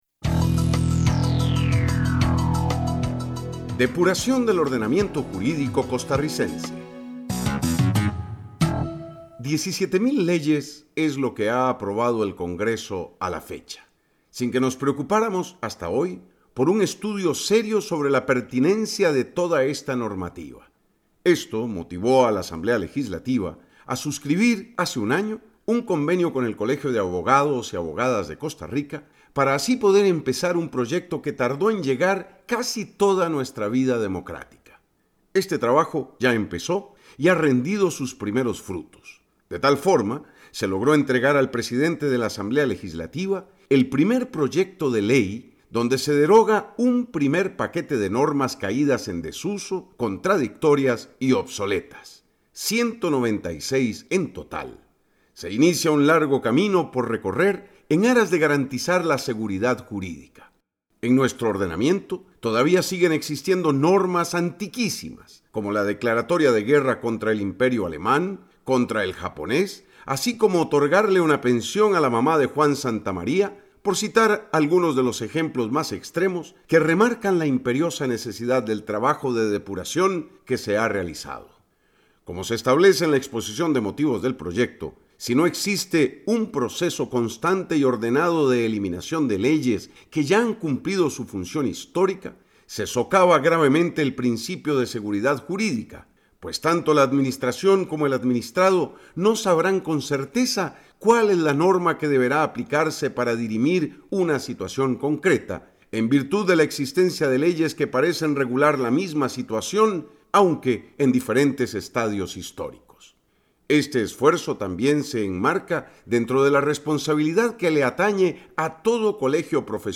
Comentarista Invitado